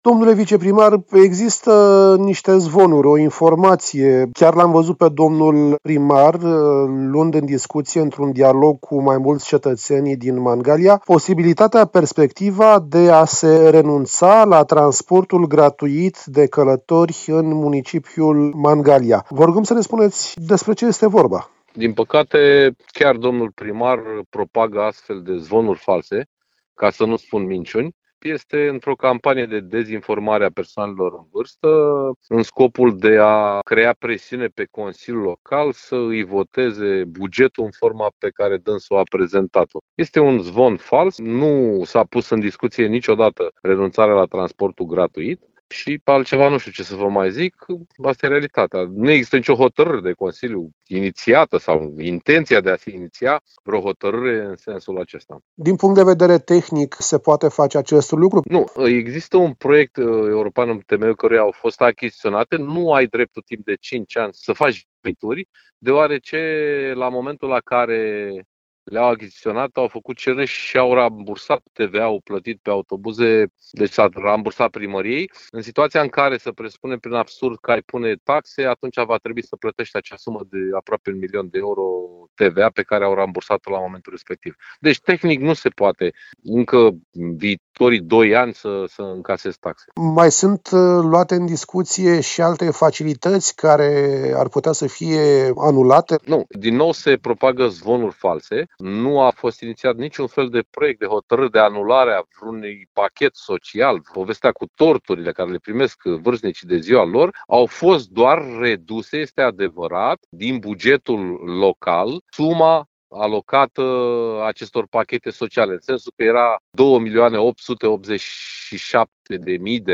AUDIO | Teme de interes pentru Mangalia. Interviu cu viceprimarul municipiului, Paul Foleanu